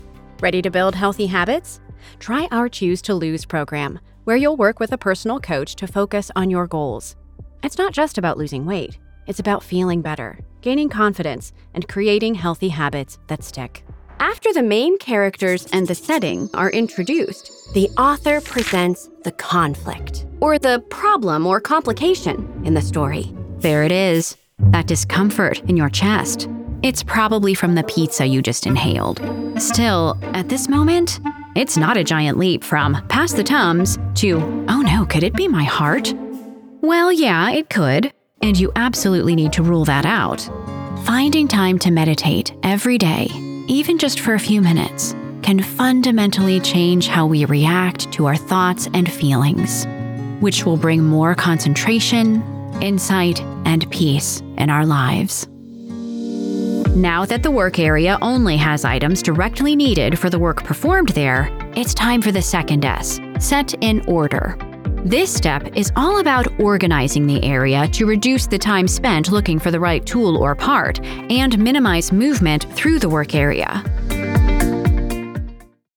I'm an American Midwest GenXer with a mid-pitch, feminine, relatable voice, AND a three octave range to pull from for kids, creatures, and (lots) of old ladies. I'm particularly good at genuine, conversational reads, but I'm not afraid of difficult emotional scripts, and I love doing game efforts and emotes.
Has Own Studio